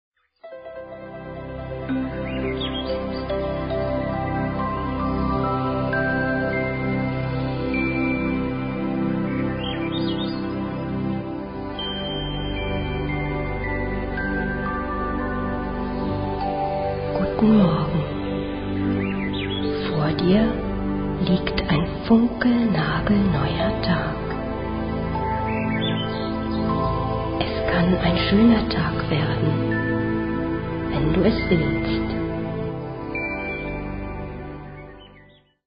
in kindgerechter Form